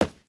sounds / material / human / step / t_concrete2.ogg
t_concrete2.ogg